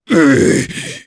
Kaulah-Vox_Damage_jp_01.wav